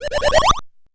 M1_HEN_JUMP_FUWA.wav